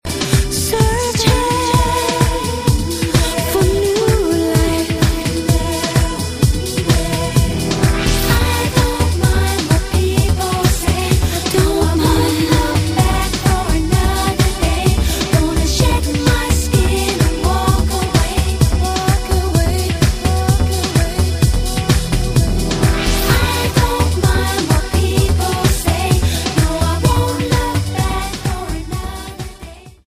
D Note